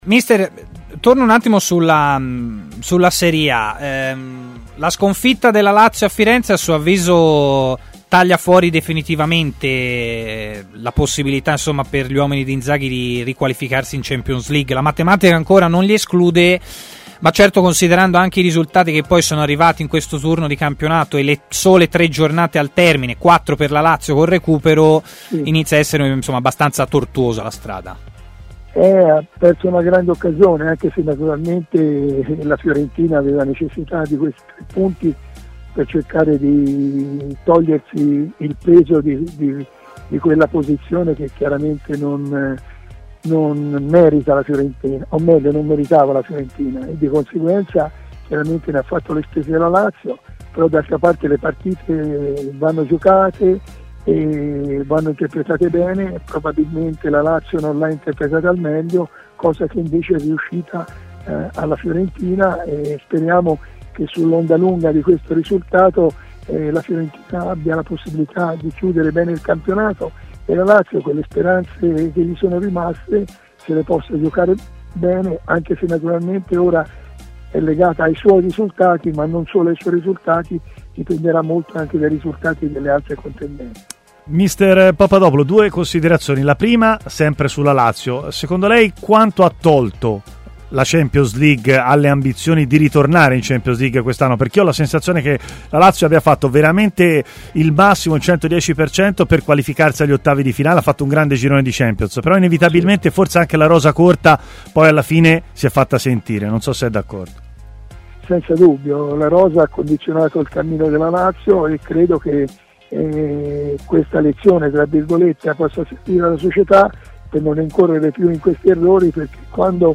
Intervenuto ai microfoni di TMW Radio, Giuseppe Papadopulo ha parlato anche della sfida e, in particolare, dell'ultima sconfitta contro la Fiorentina: "La Fiorentina cercava tre punti per togliersi da quella posizione e ne ha fatto le spese la Lazio.